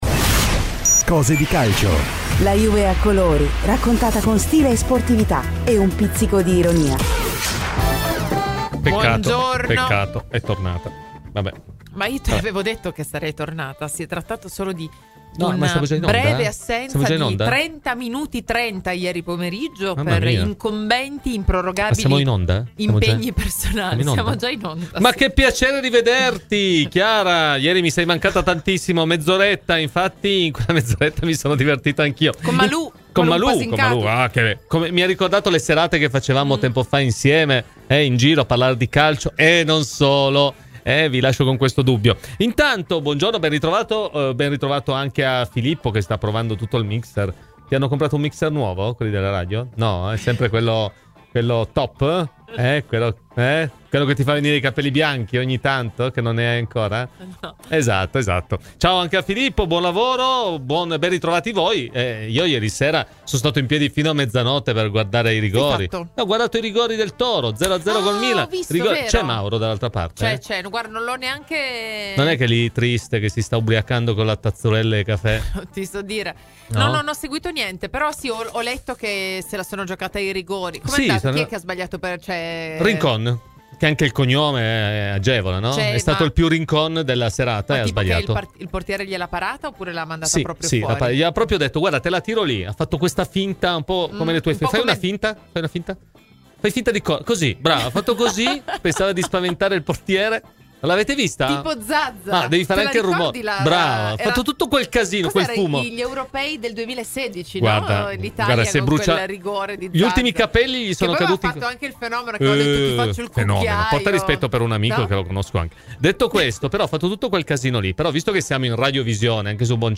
Ospiti